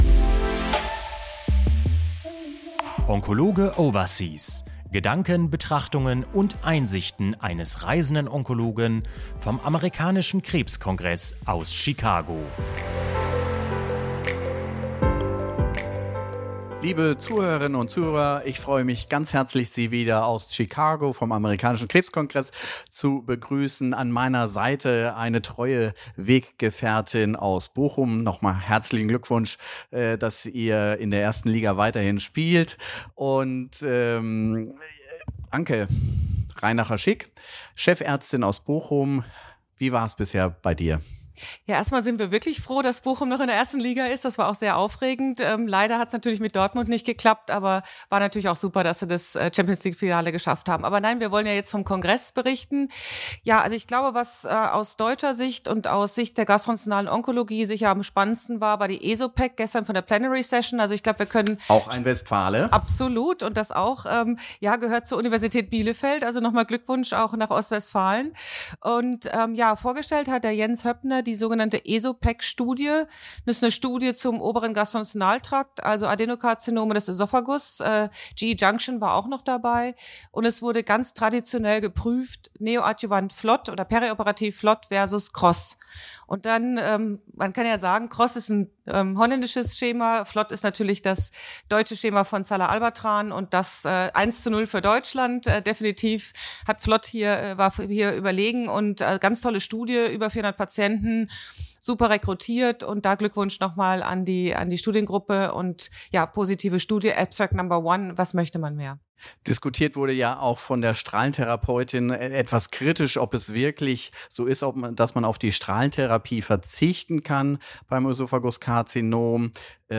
Abschließend geben die beiden Gesprächspartner einen Ausblick, mit welchen weiteren Ergebnis-Präsentationen noch kurz vor Kongressende zu rechnen ist.